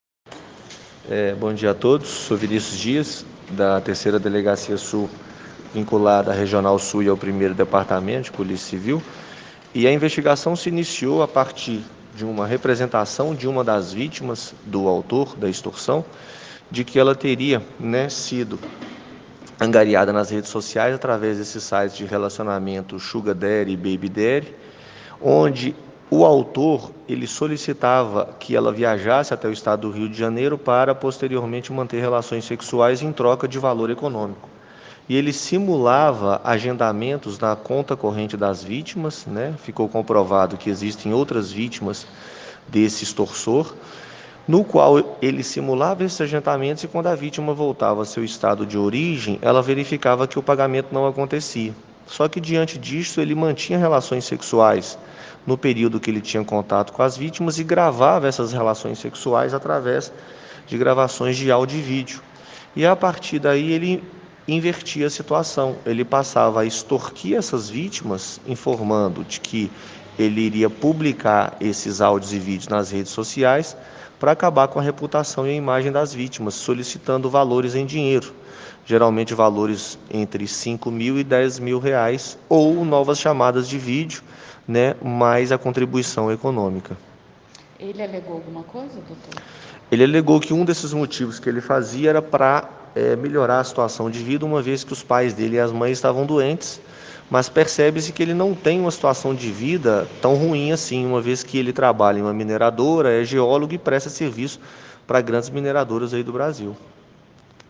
Coletiva.ogg